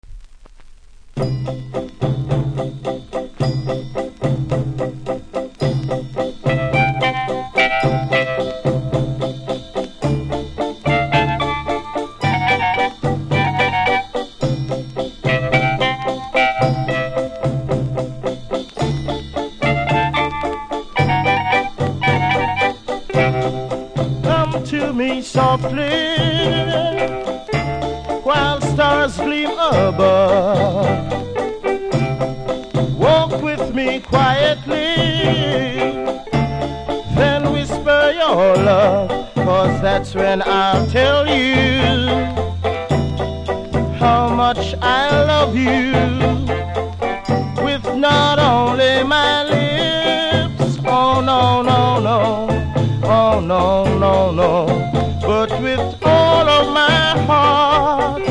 キズもノイズも少なめなので試聴で確認下さい。